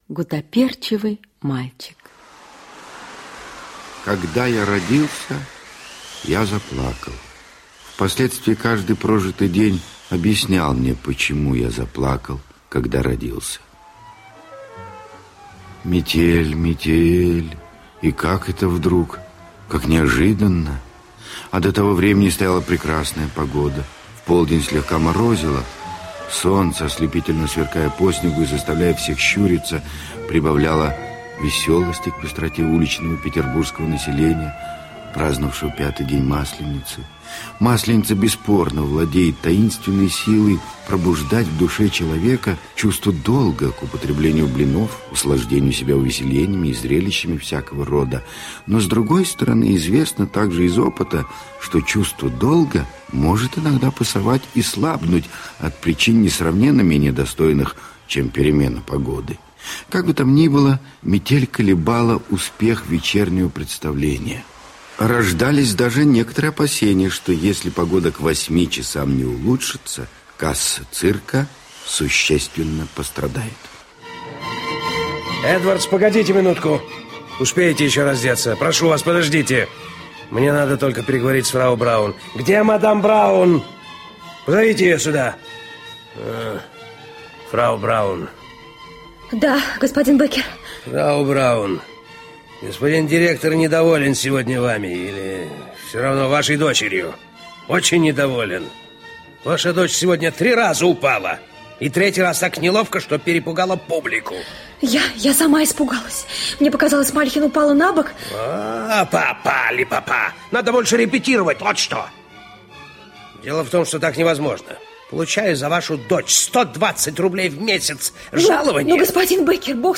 Гуттаперчевый мальчик - аудио повесть Григоровича - слушать онлайн